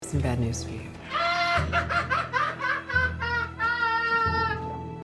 Play Joaquin Phoenix Laugh - SoundBoardGuy
joaquin-phoenix-laugh.mp3